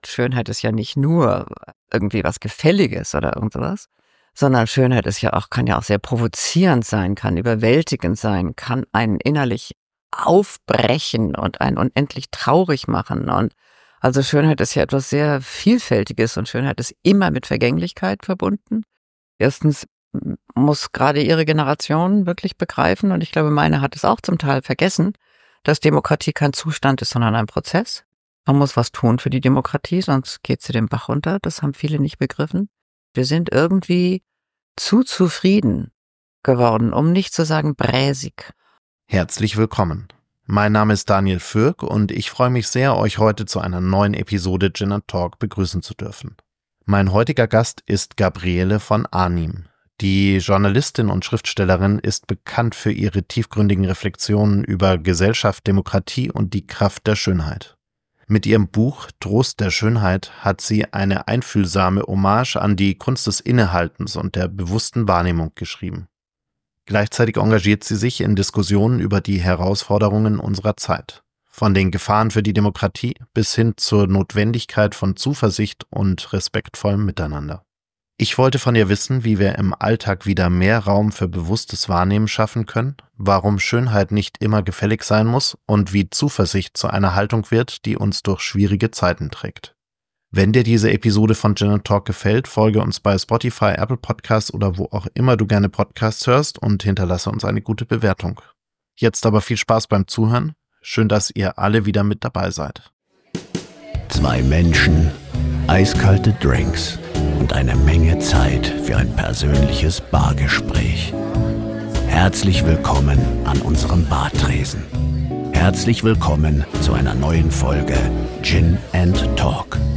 Zwei Menschen, eiskalte, perfekt gemixte Drinks und eine Menge Zeit für inspirierende Bargespräche: Mach es Dir an unserem Tresen gemütlich und lerne interessante Menschen mit unterschiedlichsten Passionen, Professionen und Geschichten kennen!